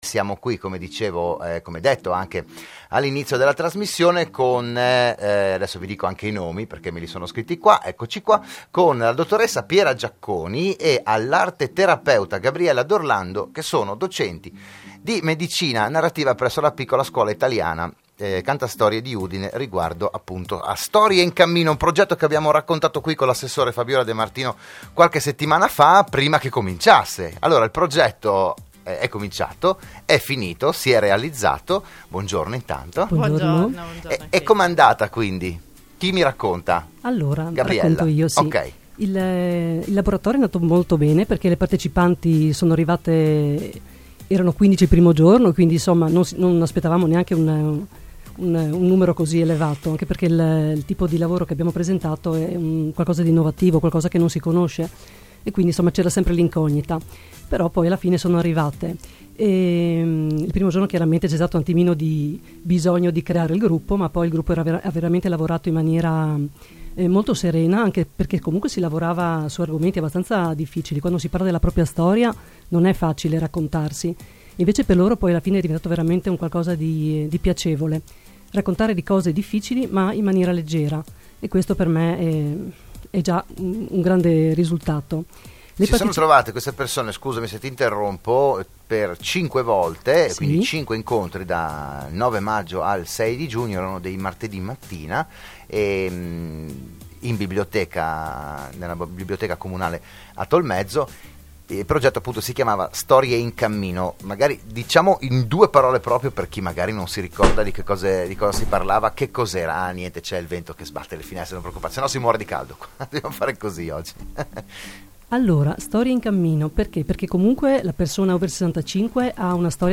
Il PODCAST dell'intervento a "RadioAttiva" di Radio Studio Nord